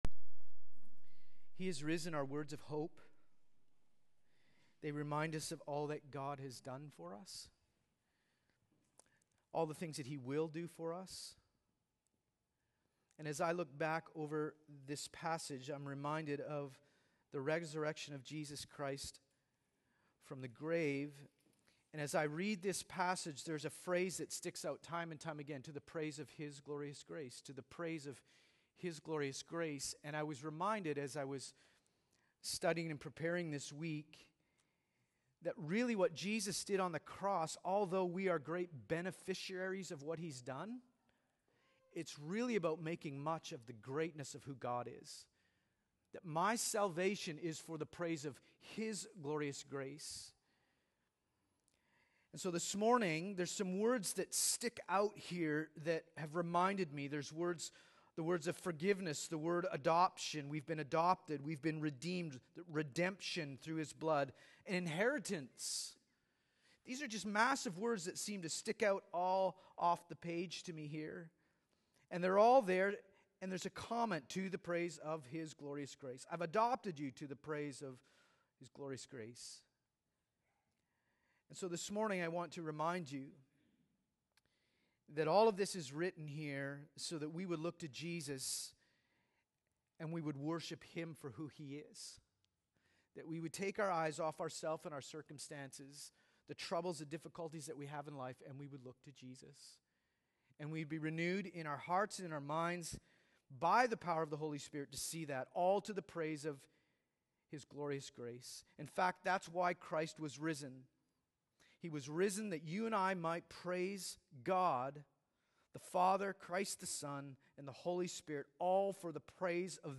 Sermons | Resonate Community Baptist Church